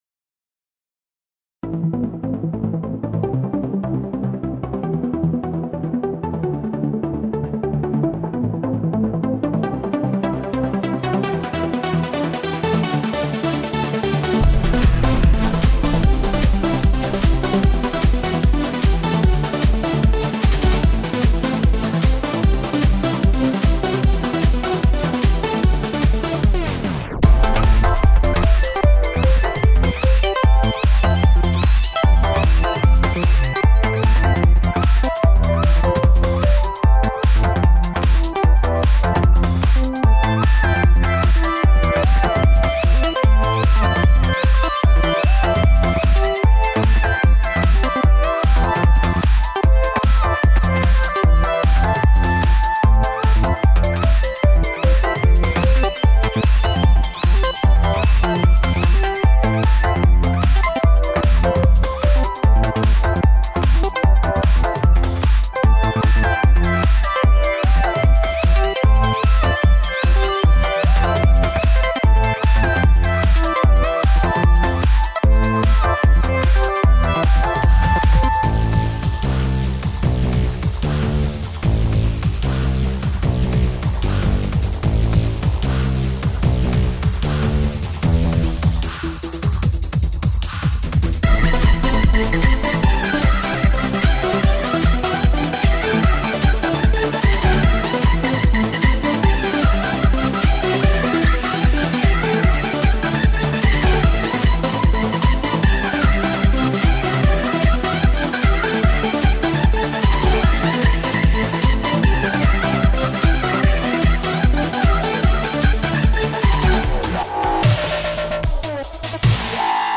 EDM ダンス 戦闘